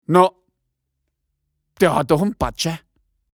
Blitzschnell ertönen die passenden Fluchworte aus dieser Maschine, die garantiert immer passen – zu Hause, auf der Arbeit, unterwegs.
Schlagworte Bayern • Fluchwörter • Franken • Fränkische Beleidigungen • Fränkische Schimpfwörter • Geschenk • Humor • Partyspaß